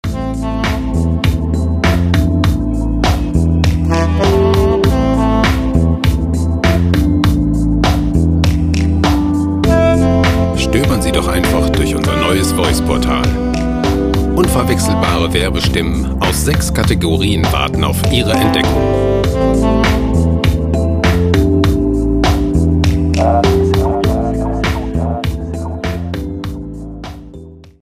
gemafreie Chillout Loops
Musikstil: Chillout
Tempo: 100 bpm